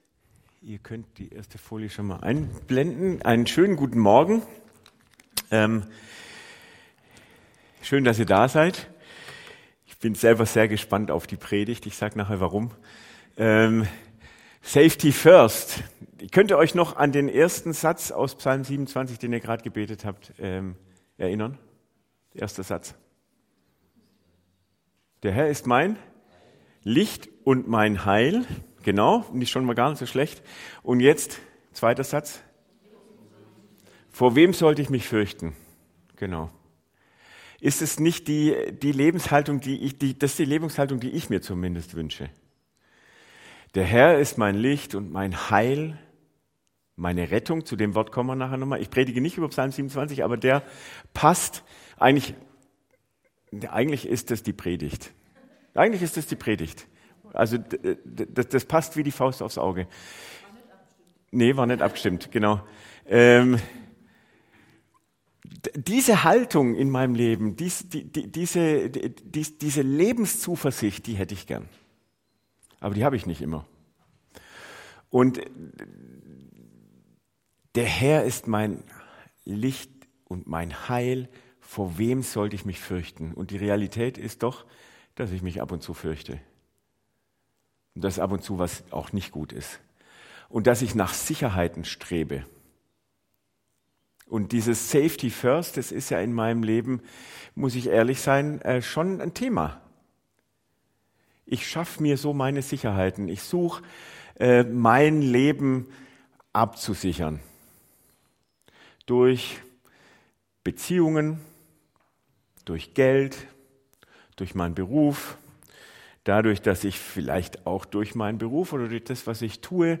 Predigt 01.06.2025 - SV Langenau